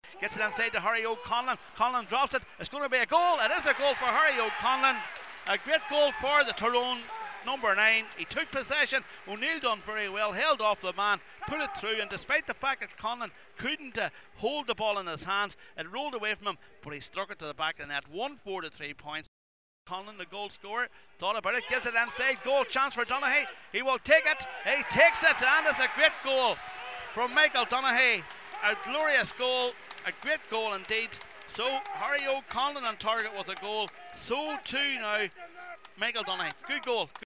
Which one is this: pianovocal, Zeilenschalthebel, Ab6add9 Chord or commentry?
commentry